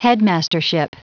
Prononciation du mot headmastership en anglais (fichier audio)
Prononciation du mot : headmastership